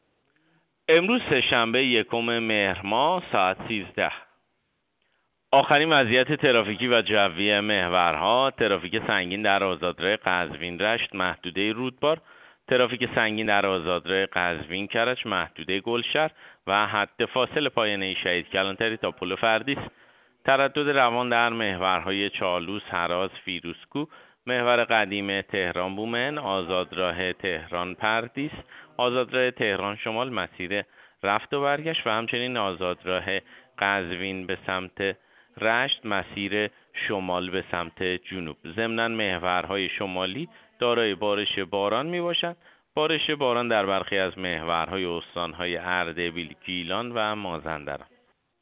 گزارش رادیو اینترنتی از آخرین وضعیت ترافیکی جاده‌ها ساعت ۱۳ یکم مهر؛